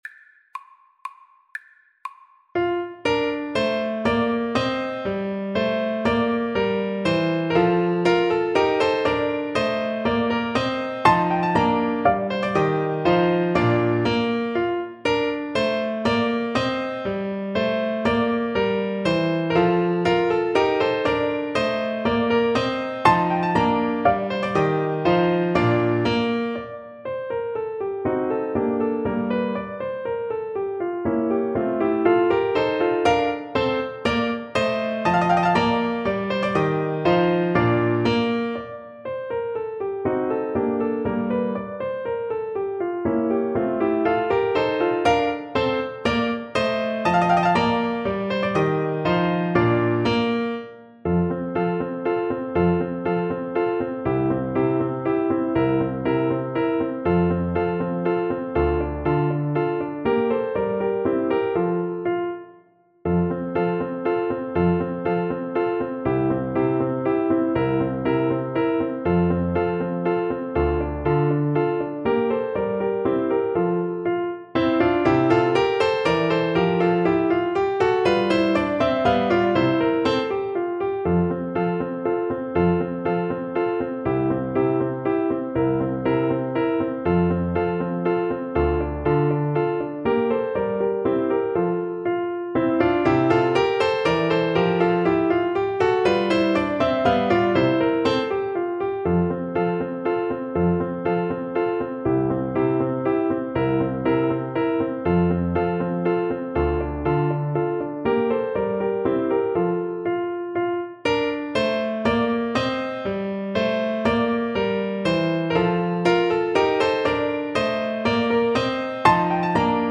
Classical Mozart, Wolfgang Amadeus Eine Kleine Nachtmusik, mvt 3 (Menuetto) French Horn version
French Horn
Bb major (Sounding Pitch) F major (French Horn in F) (View more Bb major Music for French Horn )
3/4 (View more 3/4 Music)
= 120 Allegretto
E4-F5
Classical (View more Classical French Horn Music)